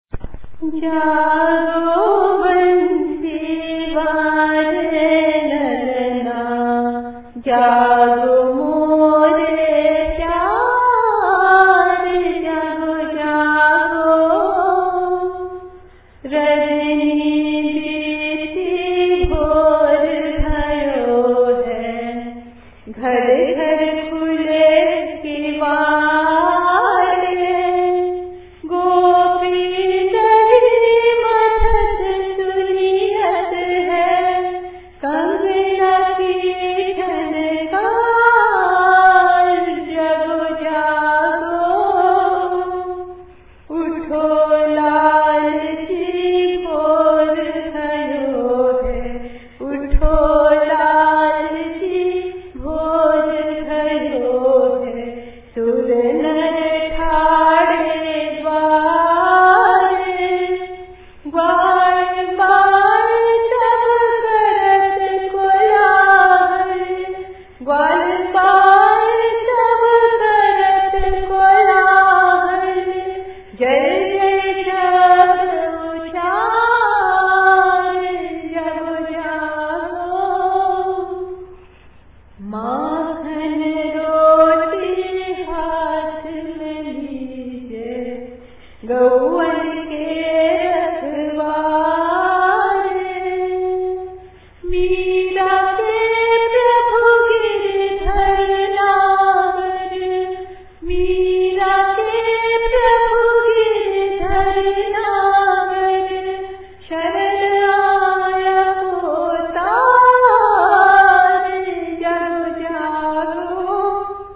Kirtan